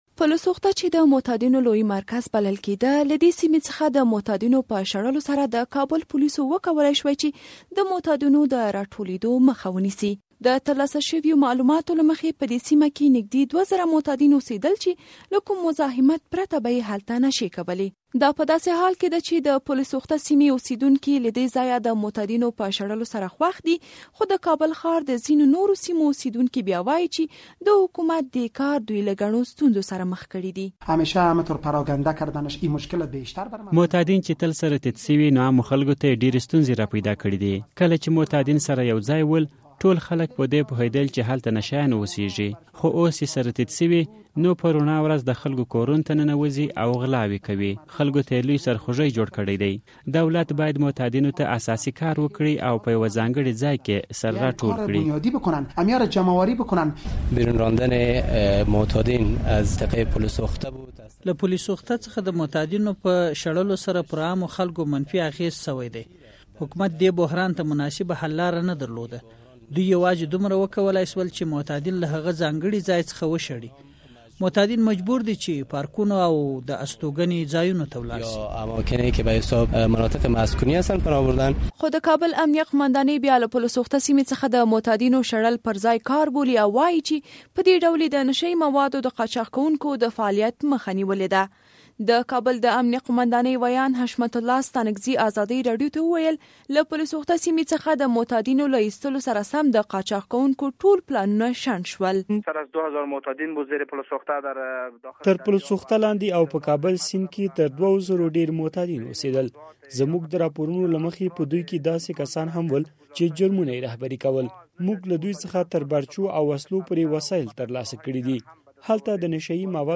راپور